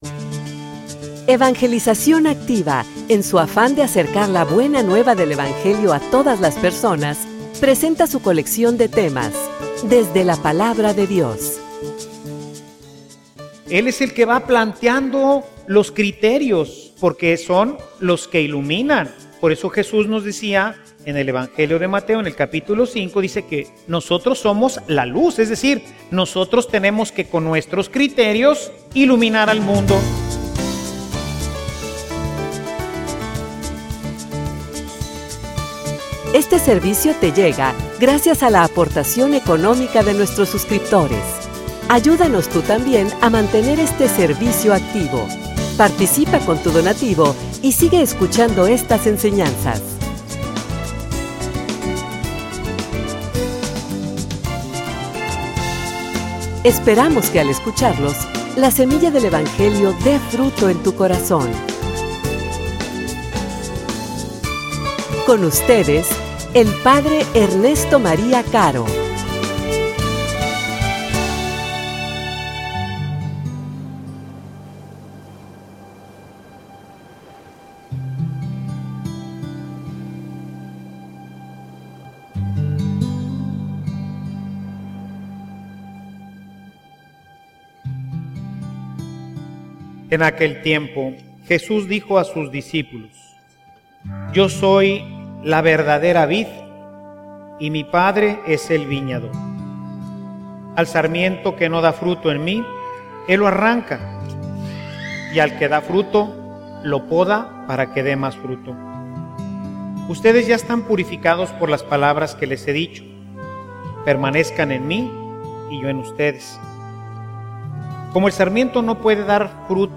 homilia_Revelacion_del_misterio.mp3